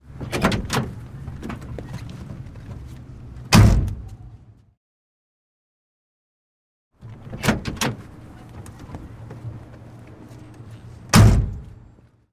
随机 " 汽车性能 汽车外门打开关闭
描述：汽车性能汽车分机门打开关闭
Tag: 开放 性能接近 汽车